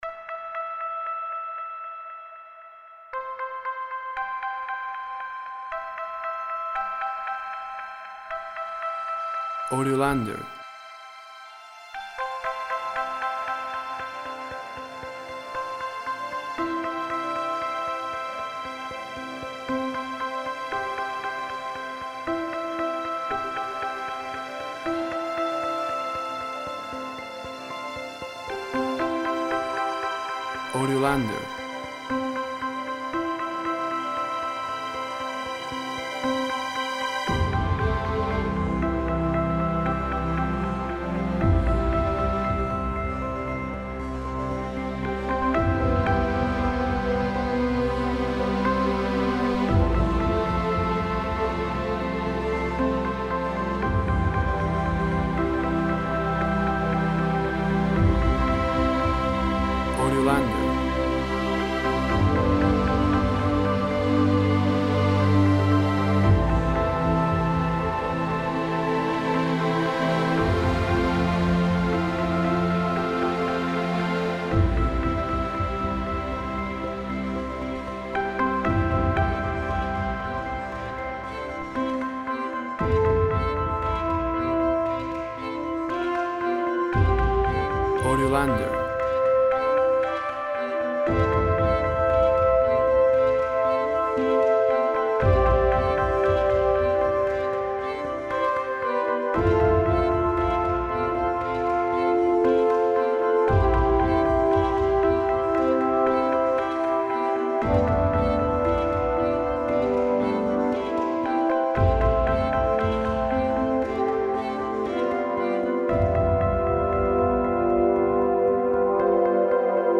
Orchestral piece, With synthesizers and sequencers.
Tempo (BPM) 116